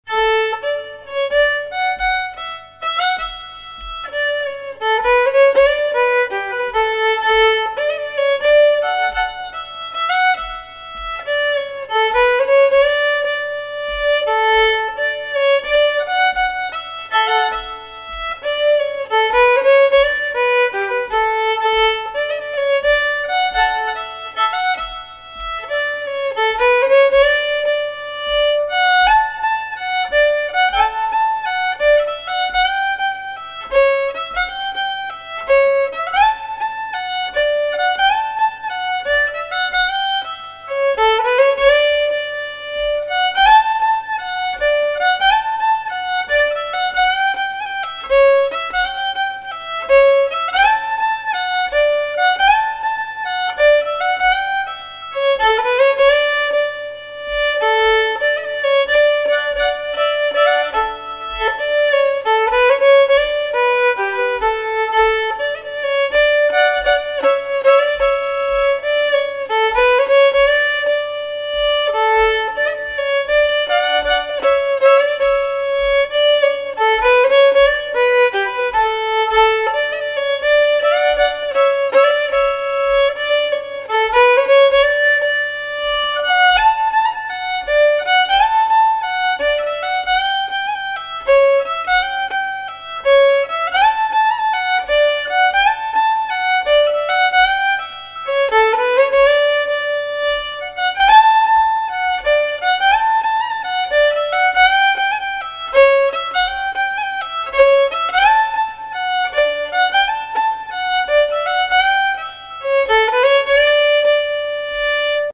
Die Mitspiel-Loops zur
3. BegleitWerkstatt für Schwedische Volksmusik